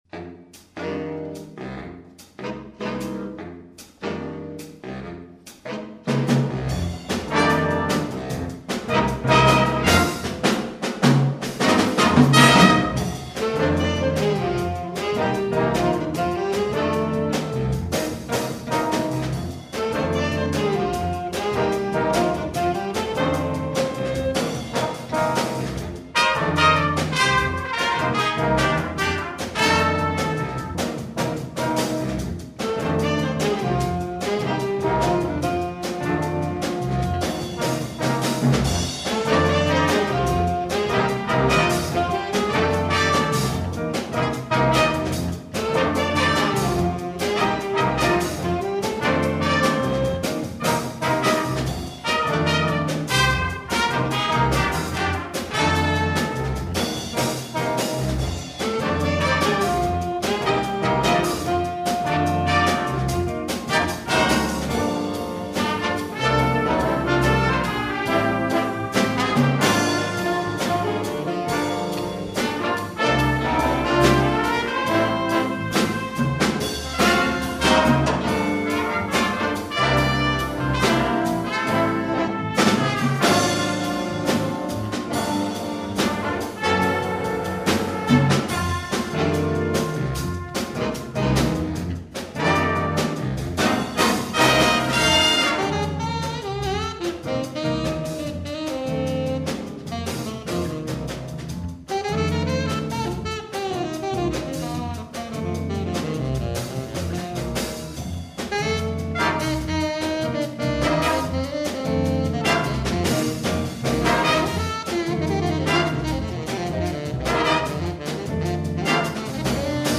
is an energetic rock chart for young jazz ensemble